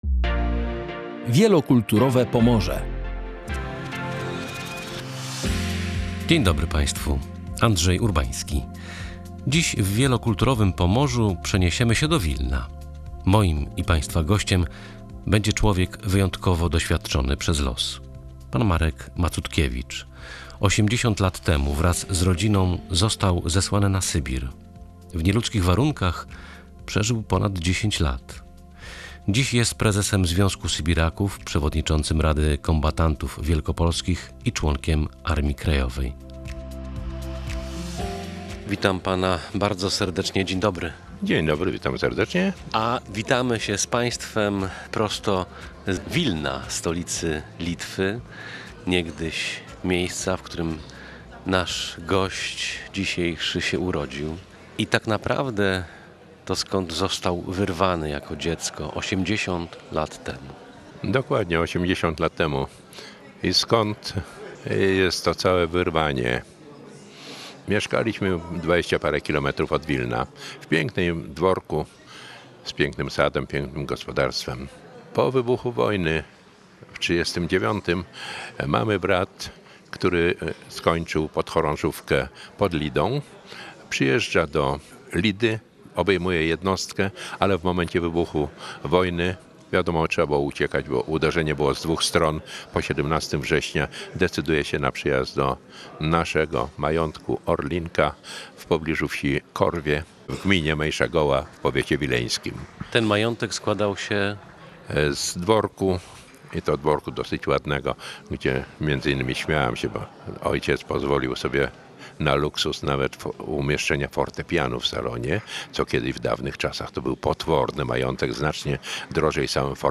rozmawiał z gościem audycji „Wielokulturowe Pomorze”